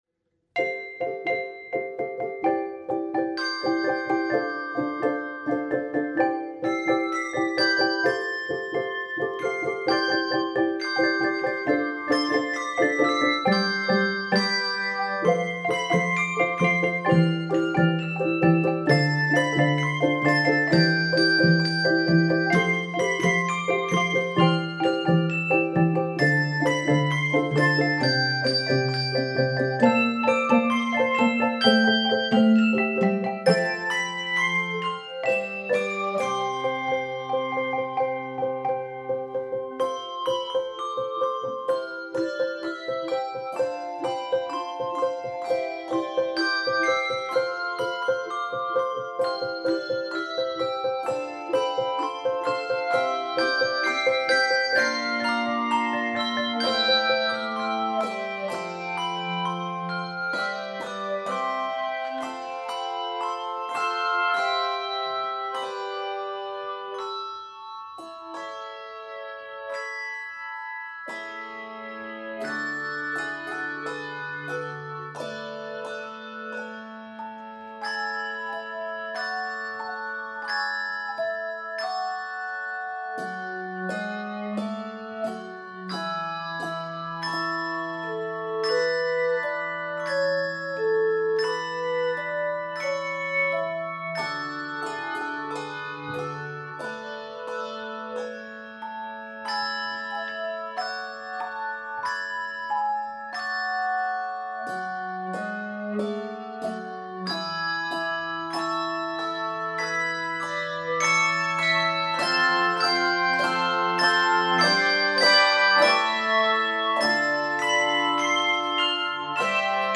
Keys of C Major and A Major.